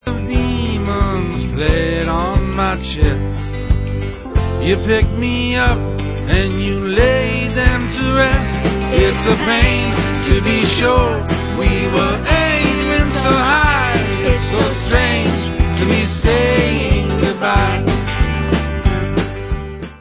NEW 2005 STUDIO ALBUM